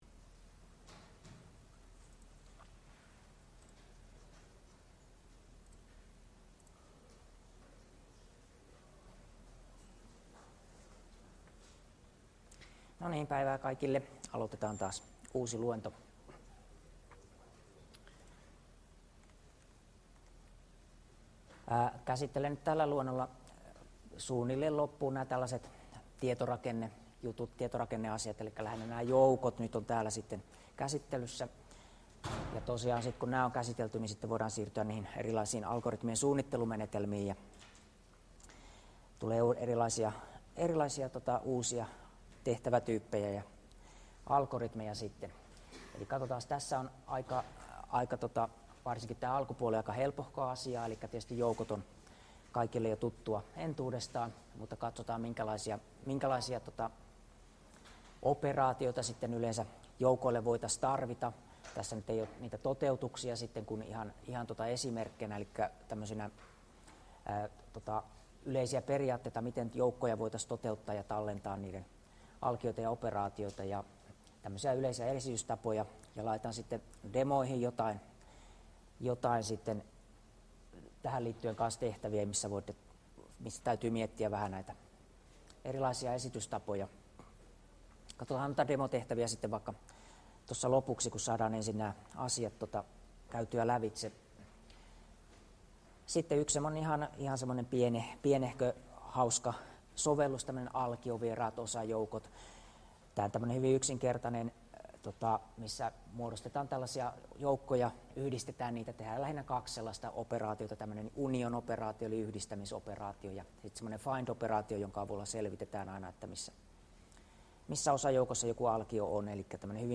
Luento 7 — Moniviestin